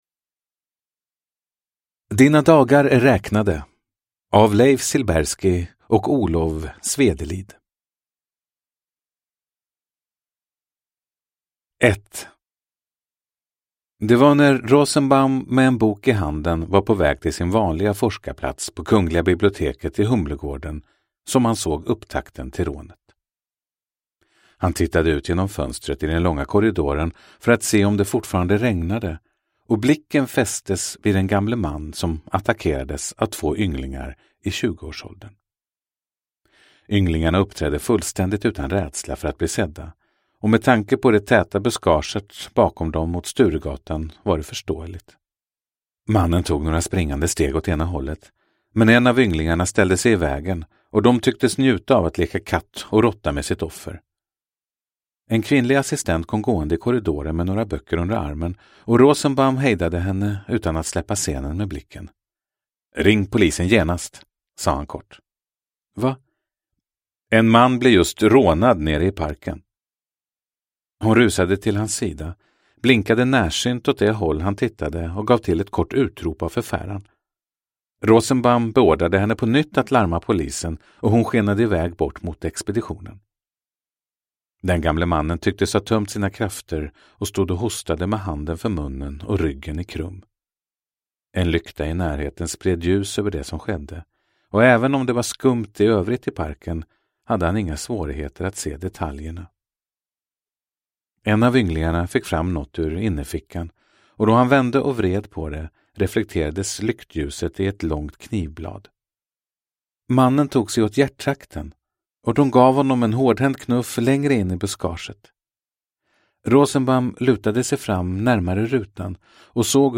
Dina dagar är räknade – Ljudbok